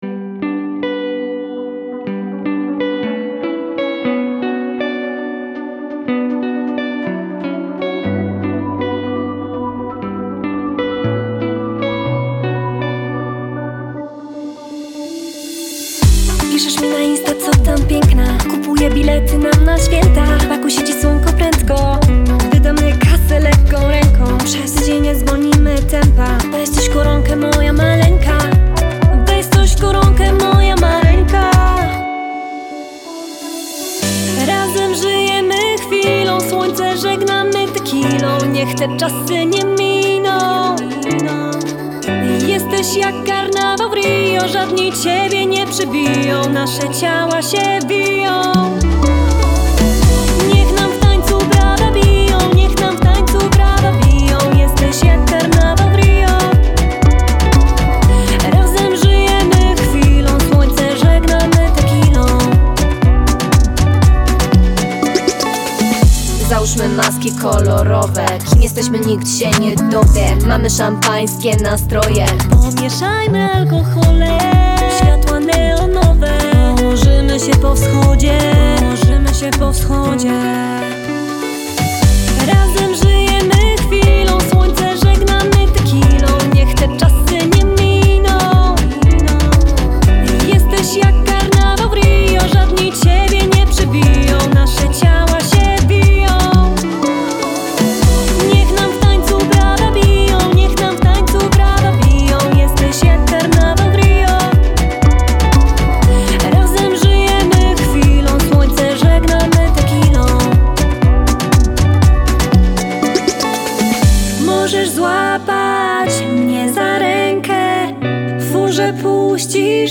Singiel (Radio)